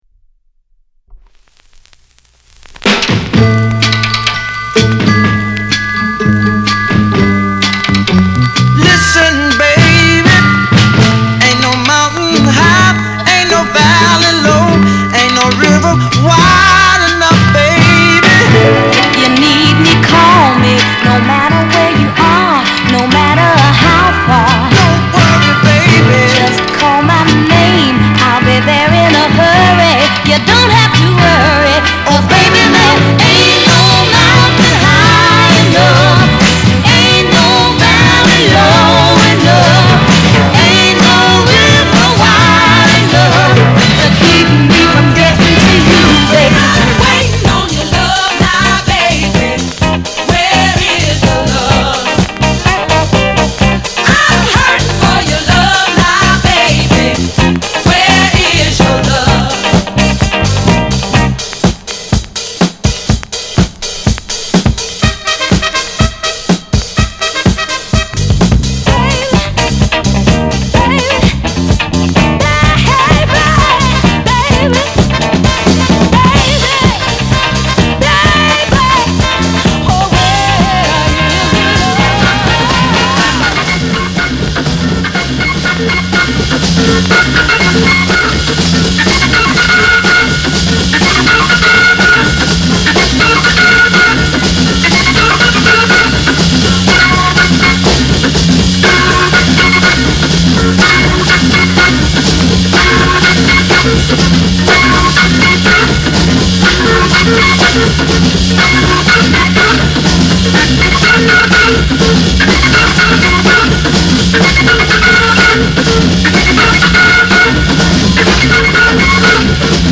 R&B、ソウル
/盤質/両面全体的に細かい傷あり/US PRESS